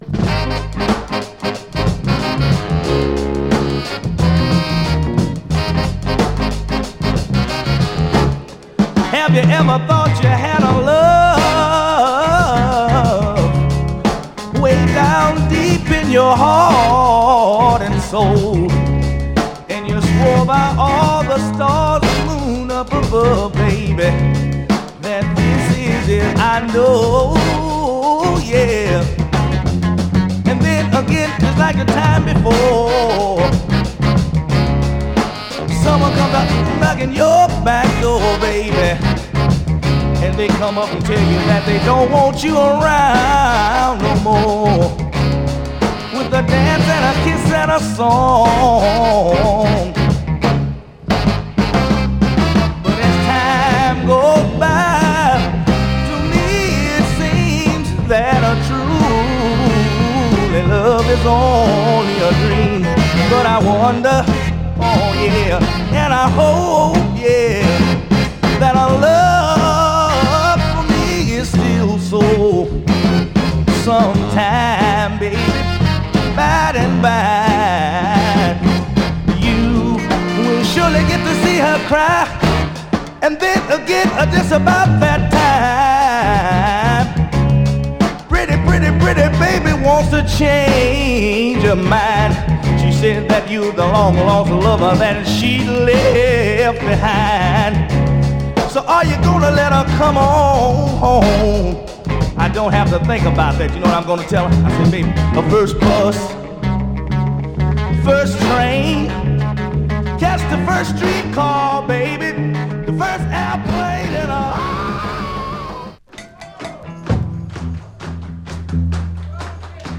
SOUL
PSYCHEDELIC FUNK〜HAPPY SOUL !!